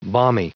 Prononciation du mot balmy en anglais (fichier audio)
Prononciation du mot : balmy